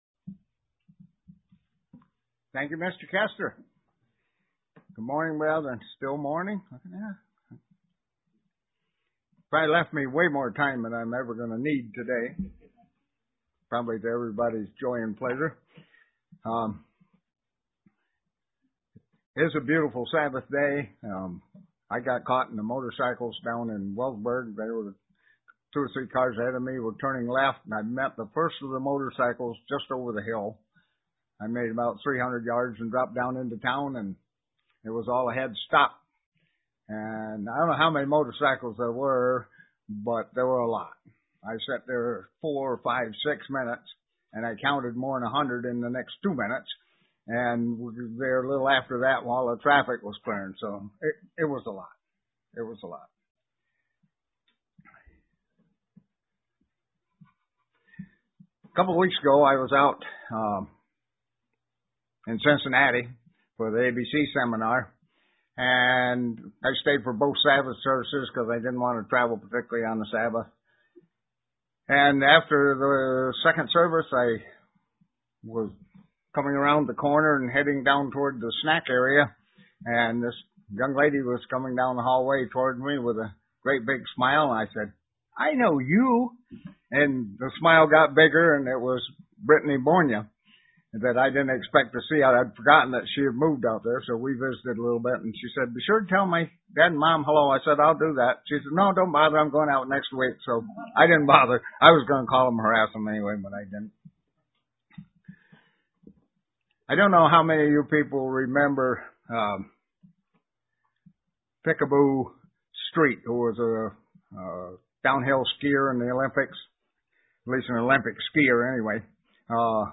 UCG Sermon Studying the bible?
Given in Elmira, NY